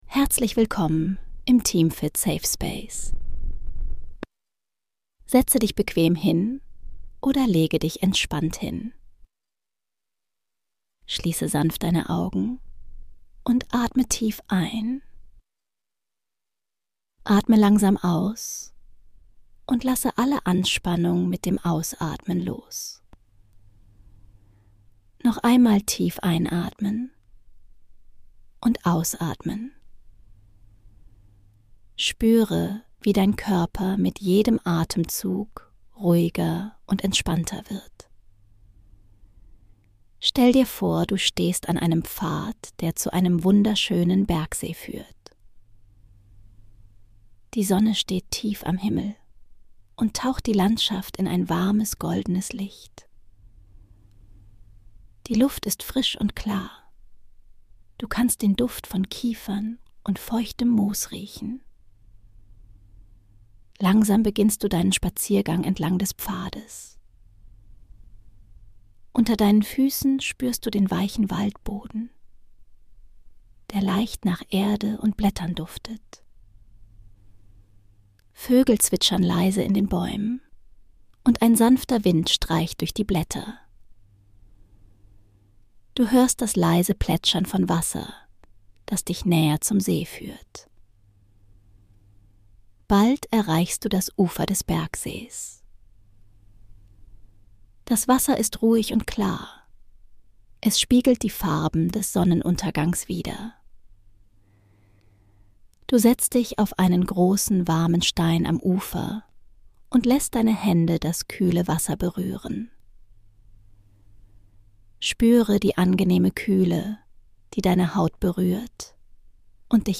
Eine beruhigende Traumreise zu einem ruhigen Bergsee bei